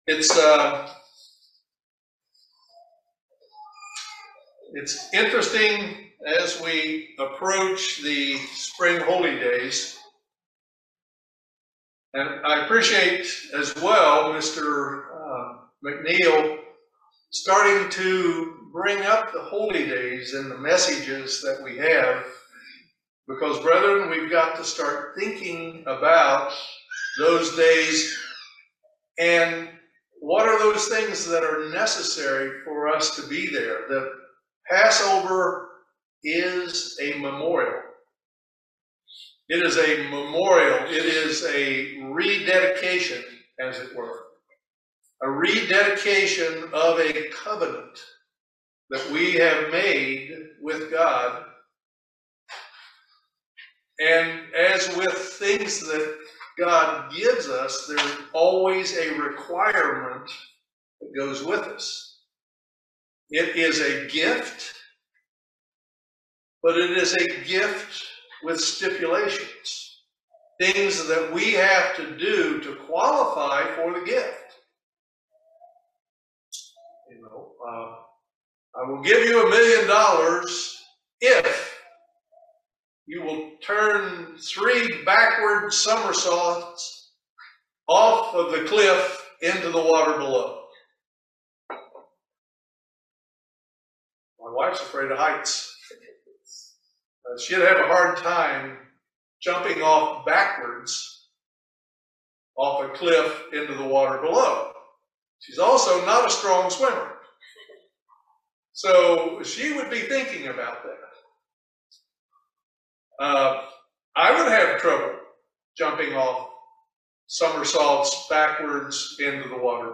This Sermon outlines seven situations that require the laying on of hands as the Holy Scriptures command.